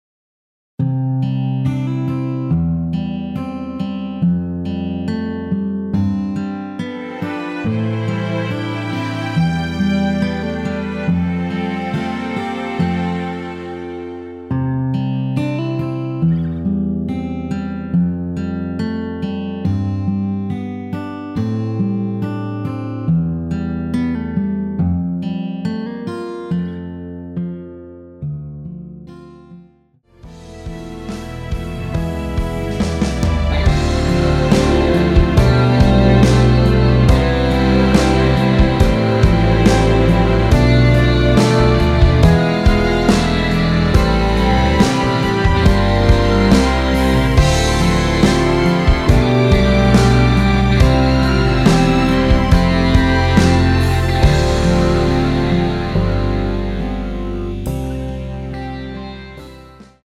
원키에서(-2)내린 MR 입니다.
앞부분30초, 뒷부분30초씩 편집해서 올려 드리고 있습니다.
중간에 음이 끈어지고 다시 나오는 이유는